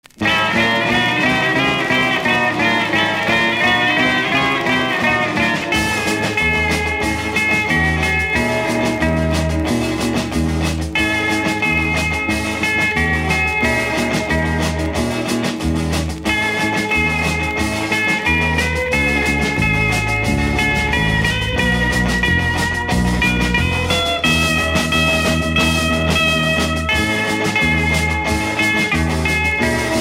Rock instrumental